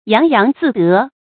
洋洋自得 注音： ㄧㄤˊ ㄧㄤˊ ㄗㄧˋ ㄉㄜˊ 讀音讀法： 意思解釋： 猶言洋洋得意。